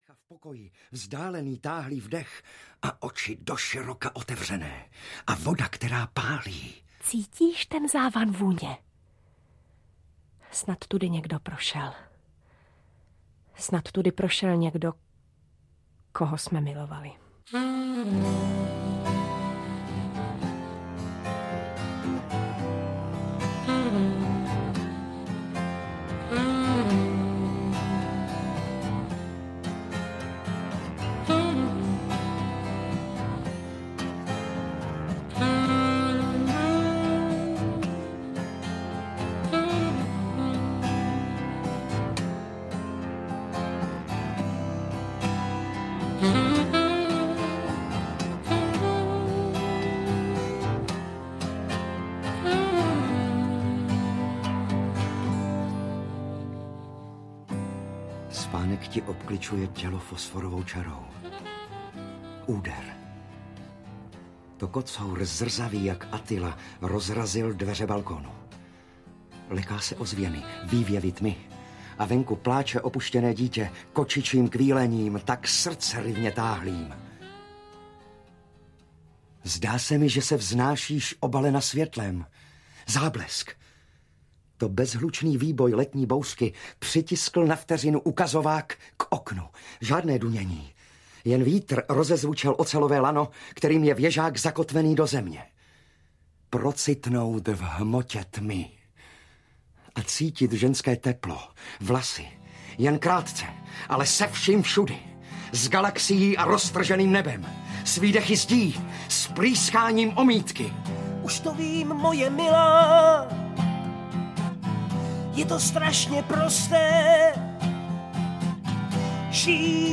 Požár na obloze audiokniha
Ukázka z knihy